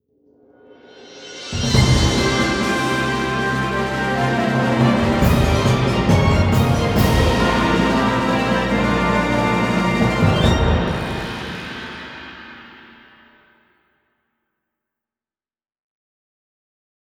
Fanfare-Novium2.wav